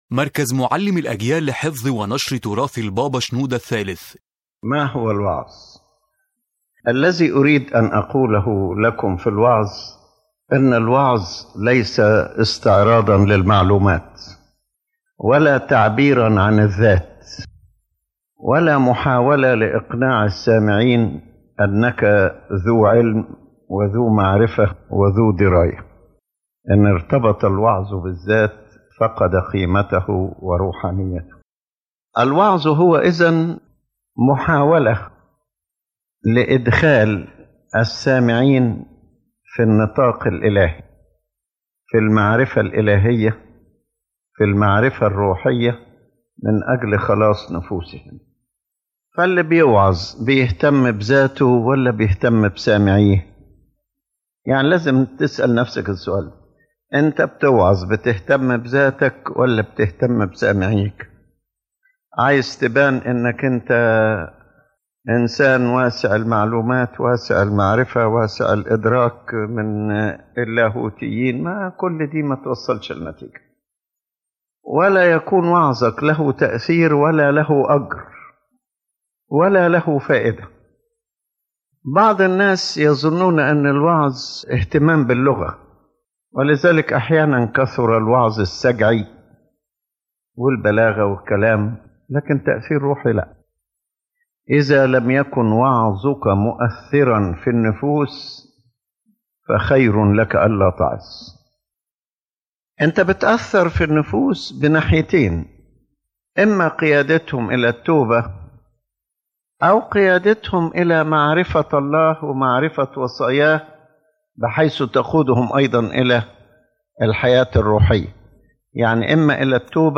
⬇ تحميل المحاضرة الرسالة العامة للمحاضرة الوعظ ليس استعراضًا للمعلومات ولا إظهارًا للذات، بل خدمة روحية تهدف إلى إدخال السامعين في معرفة الله، وقيادتهم إلى التوبة والنمو الروحي والخلاص، بروح التواضع والمحبة.